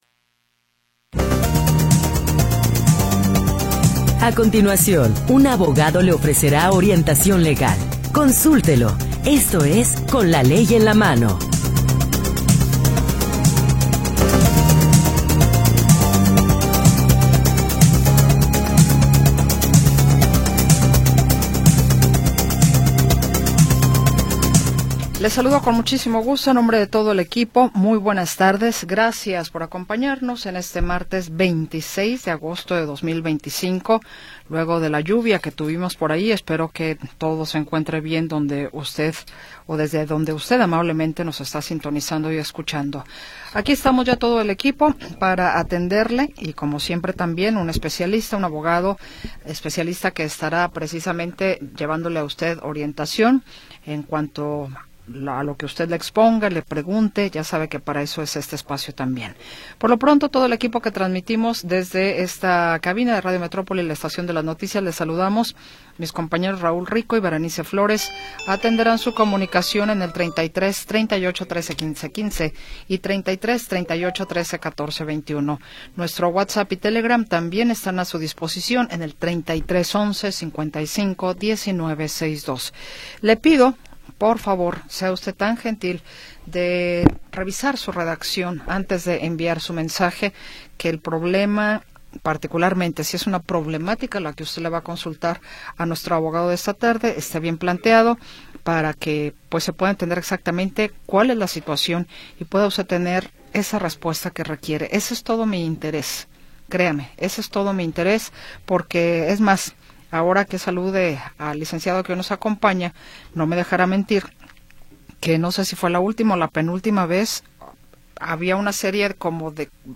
Programa transmitido el 26 de Agosto de 2025.